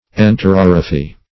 Search Result for " enterorrhaphy" : The Collaborative International Dictionary of English v.0.48: Enterorrhaphy \En`ter*or"rha*phy\, n. [Gr.
enterorrhaphy.mp3